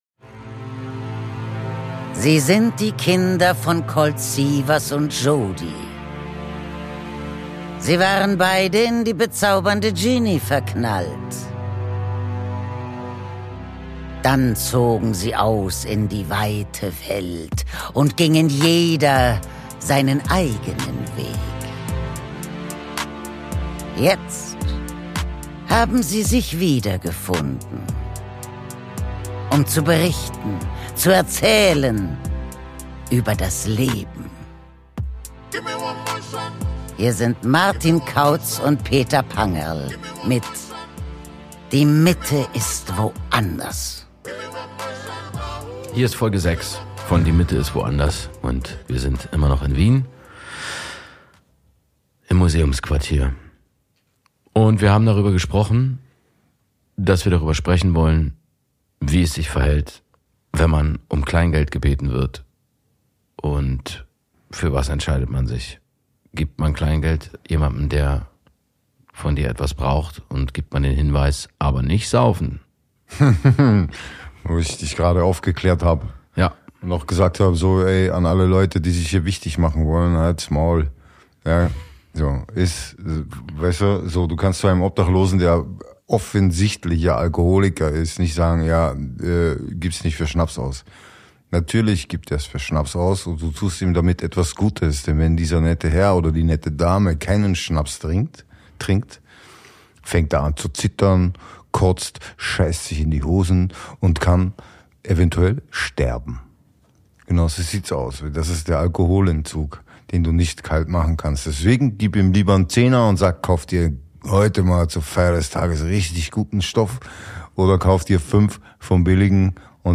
Aus dem 25h Hotel im Wiener Museumsquartier